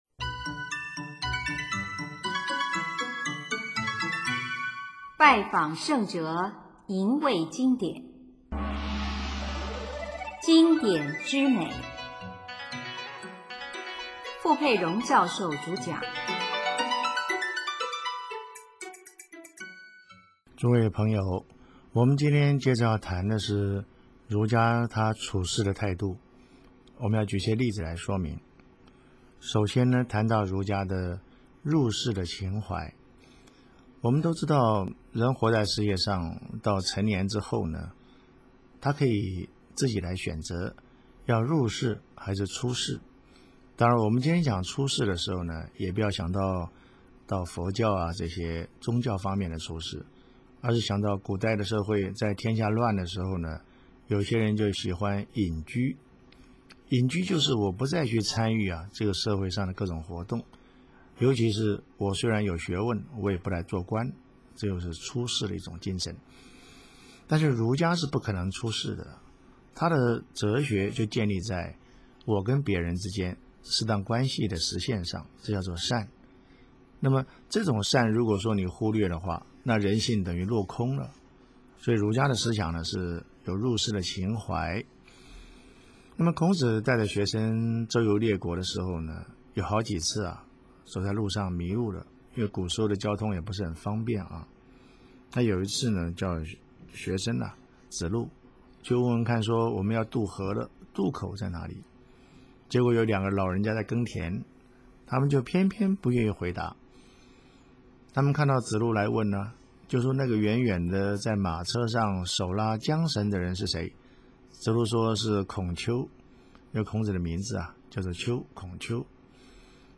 主讲：傅佩荣教授